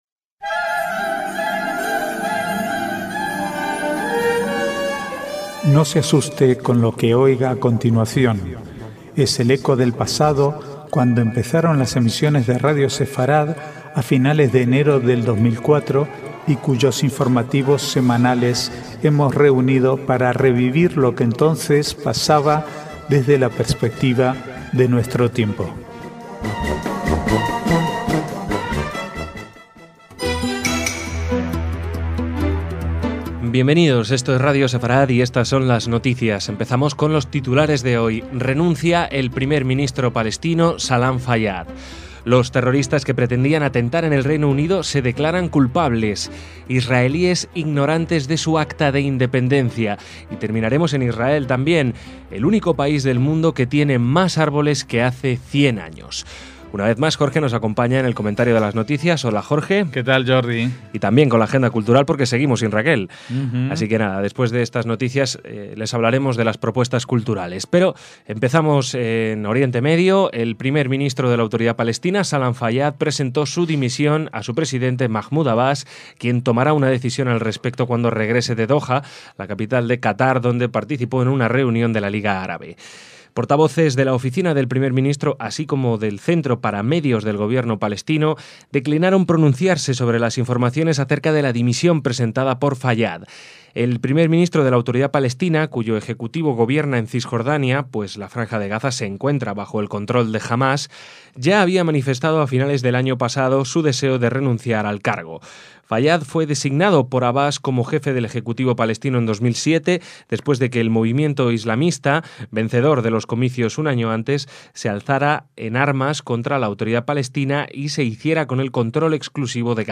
Archivo de noticias del 10 al 16/4/2013